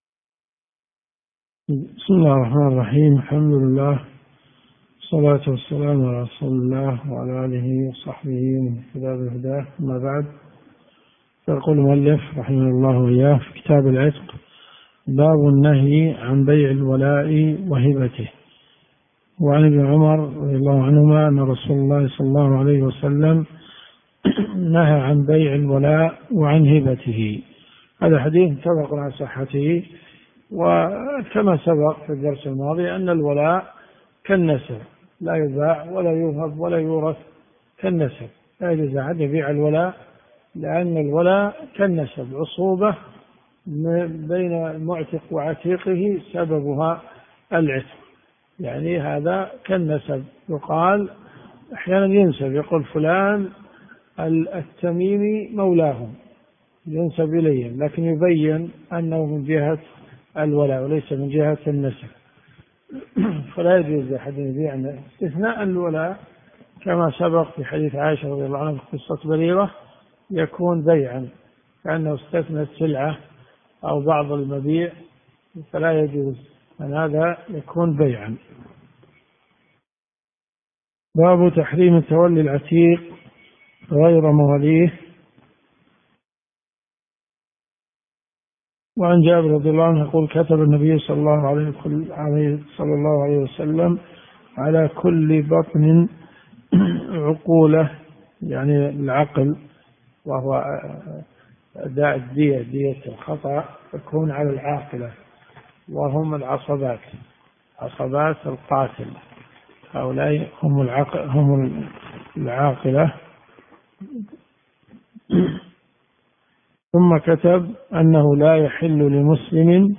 الرئيسية الكتب المسموعة [ قسم الحديث ] > صحيح مسلم .